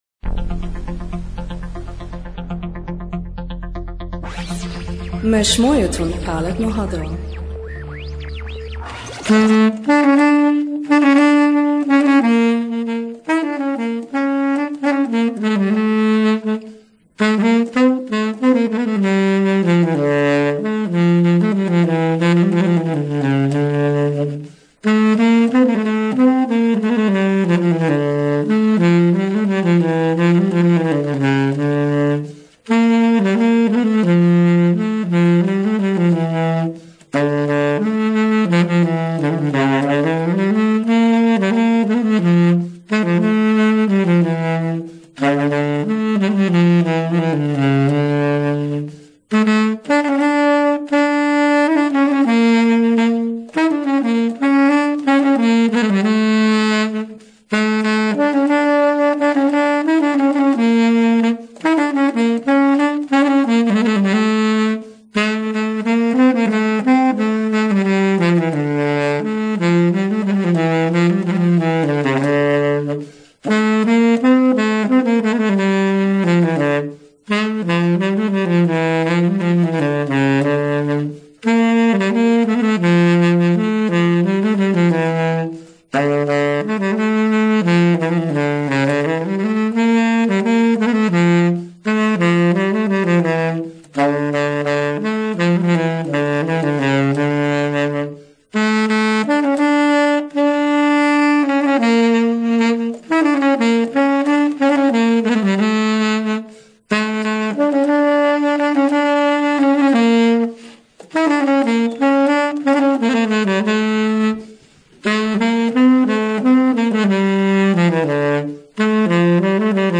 ASSYRIAN ARTIST INTERVIEWS